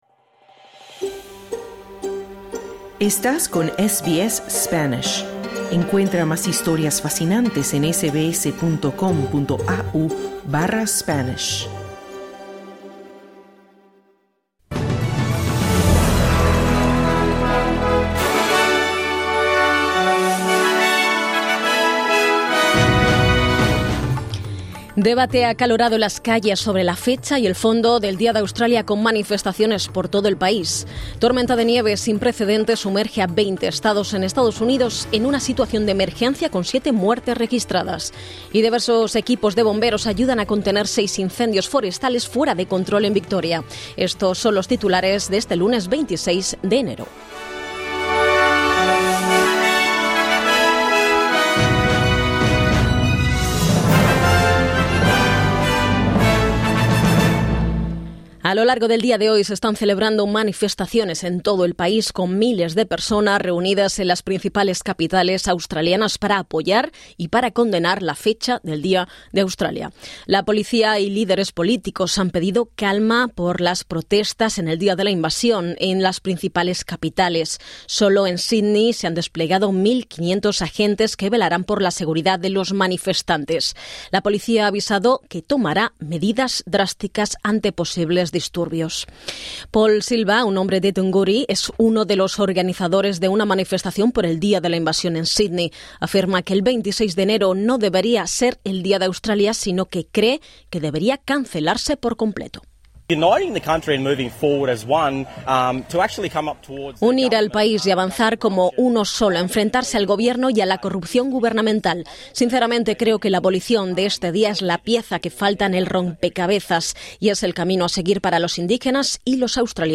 Boletín de noticias viernes 26/1/2026: A lo largo del día de hoy miles de personas se reunirán en las capitales australianas para apoyar y para condenar la fecha del Día de Australia.